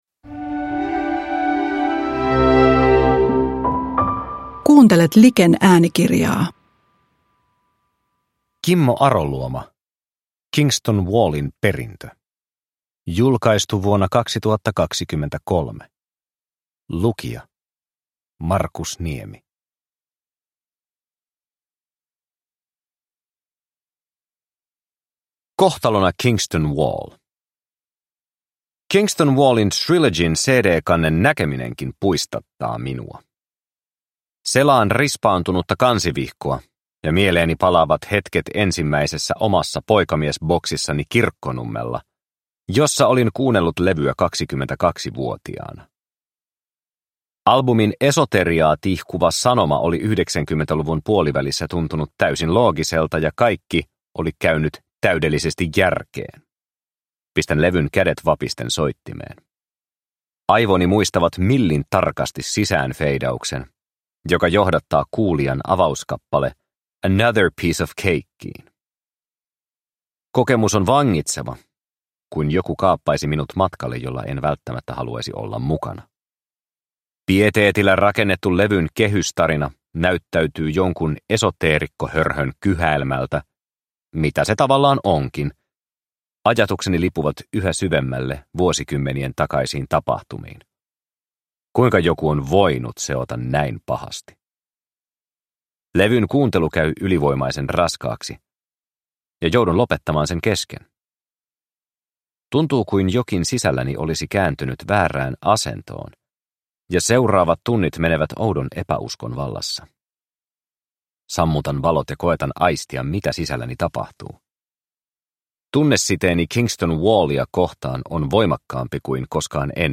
Kingston Wallin perintö – Ljudbok – Laddas ner